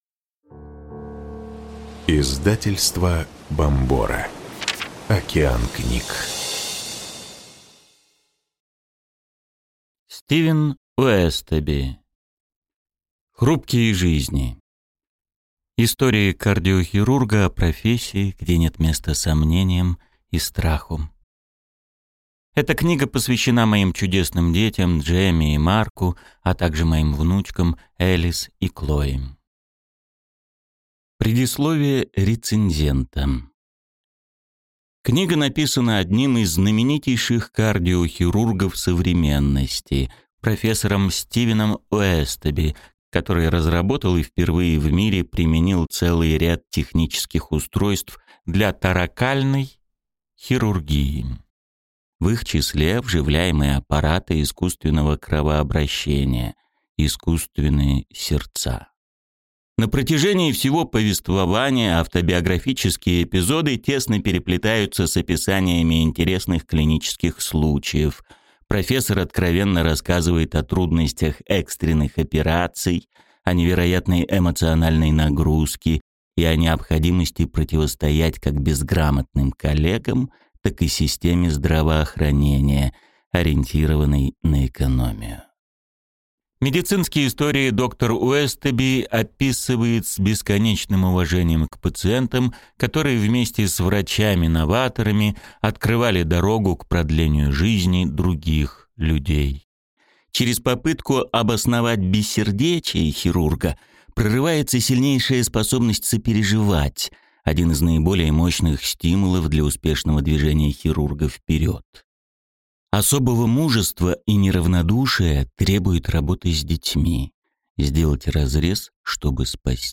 Аудиокнига Хрупкие жизни. Истории кардиохирурга о профессии, где нет места сомнениям и страху | Библиотека аудиокниг